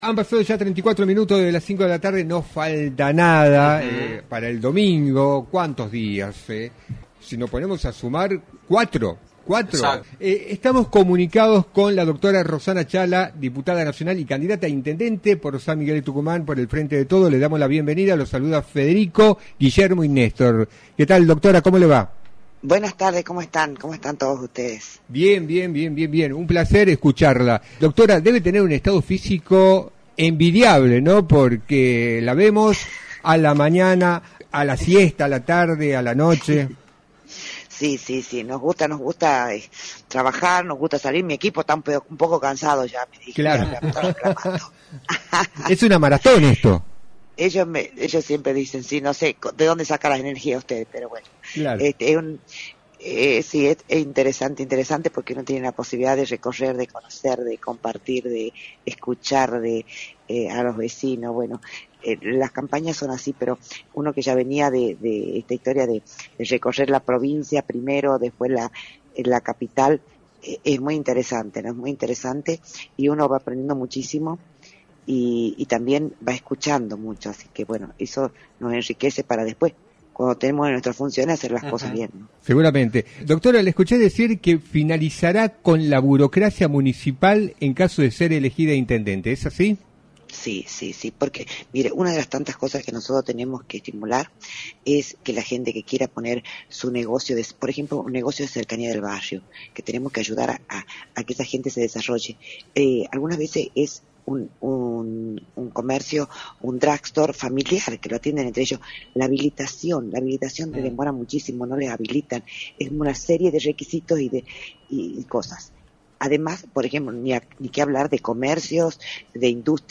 Rossana Chahla, Diputada Nacional y candidata a Intendente de la ciudad de San Miguel de Tucumán por el Frente de Todos Tucumán, analizó en Radio del Plata Tucumán, por la 93.9, el escenario electoral de la provincia y remarcó sus proyectos, en la previa de las elecciones establecidas para el próximo 11 de junio.